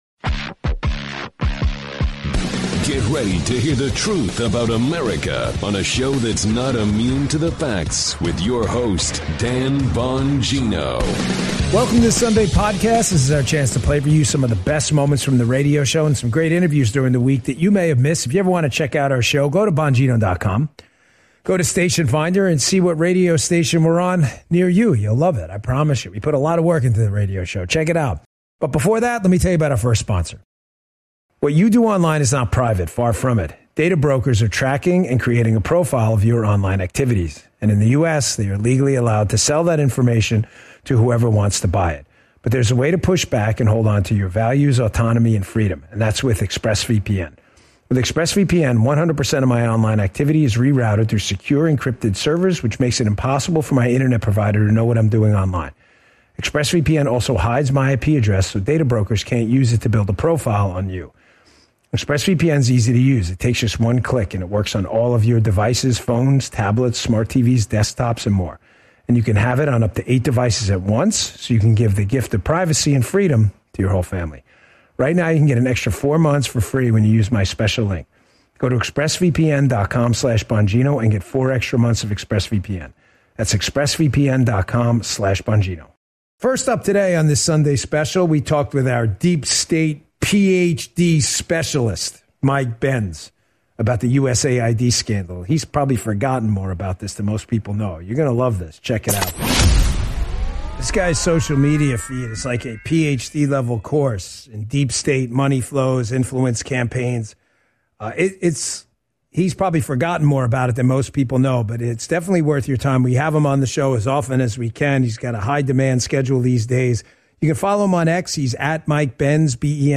The podcast hosted by Dan Bongino features highlights from his radio show and interviews.